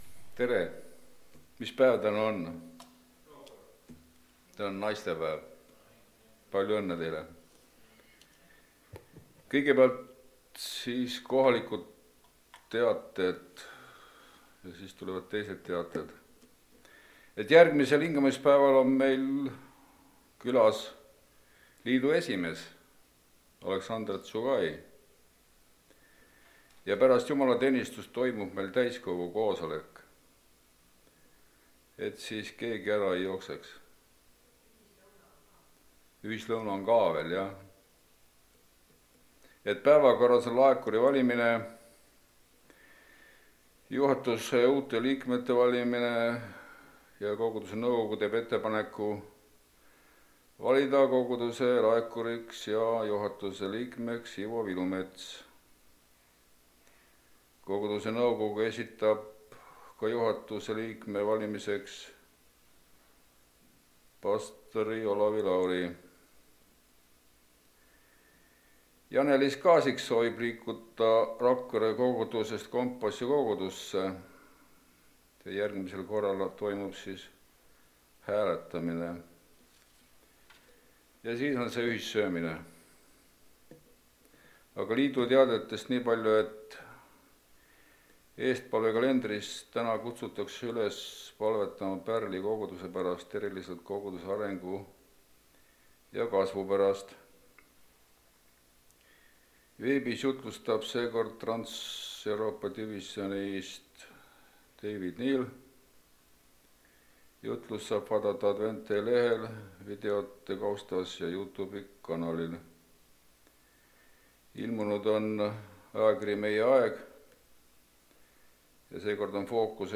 Koosolekute helisalvestused